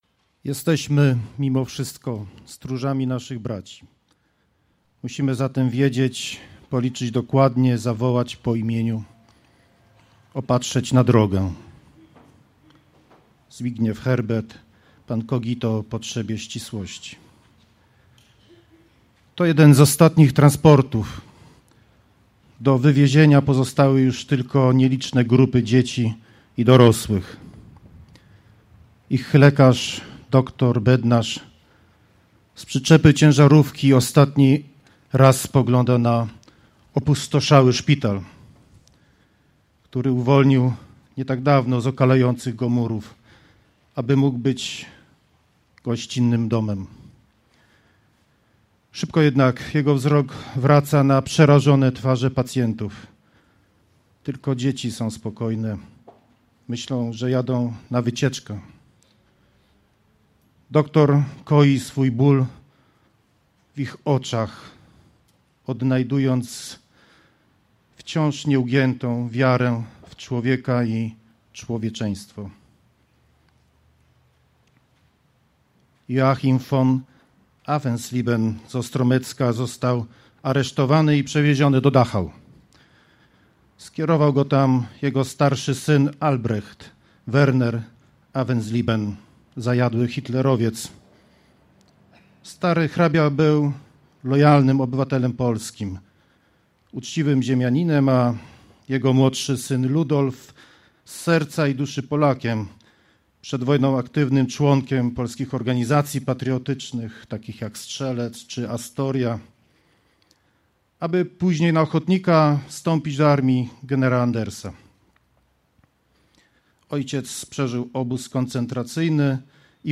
Przemówienie marszałka Piotra Całbeckiego (mp3)
przemowienie.mp3